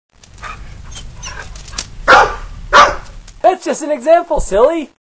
• If your want to hear us read the story (with a few sound effects) simply click on the